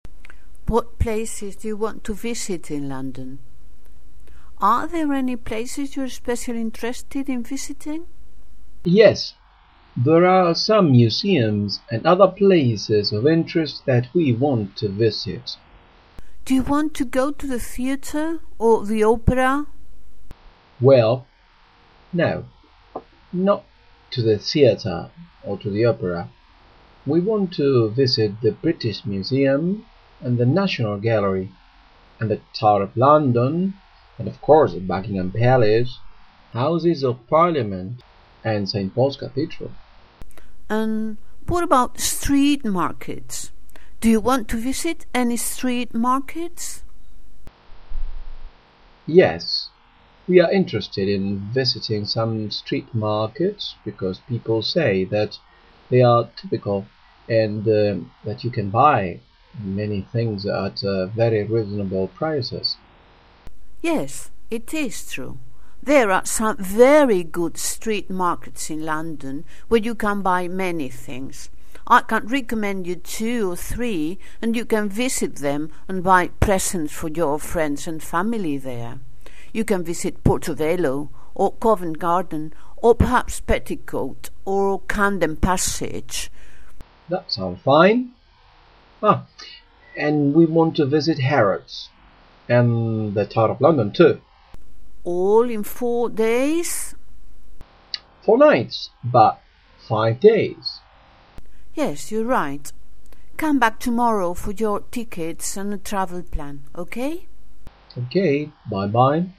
En el diálogo, la agente de viajes te ha sugerido visitar algunos "street markets" (mercadillos).